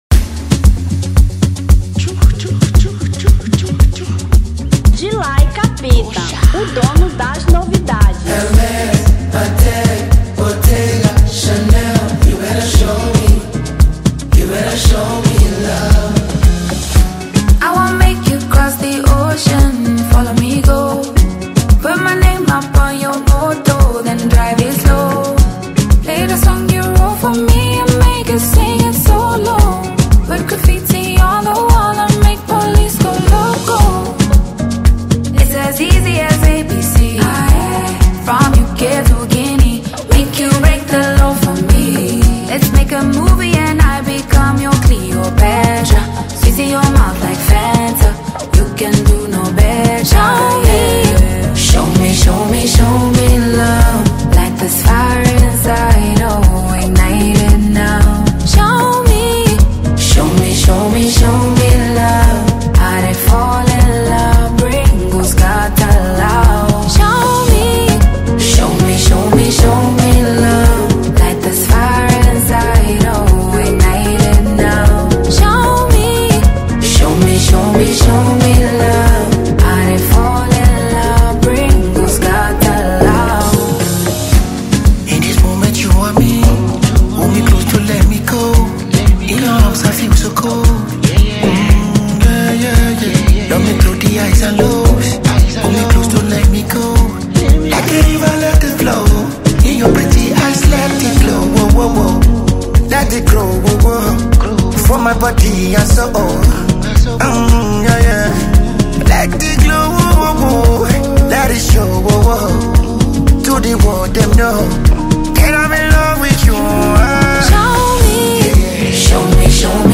Afro Beat 2025